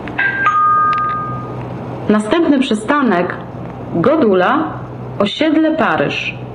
głośniki informacji pasażerskiej   wyświetlacz wewnętrzny
informacji pasażerskiej   co słychać w wagonach?
następny_przystanek.wav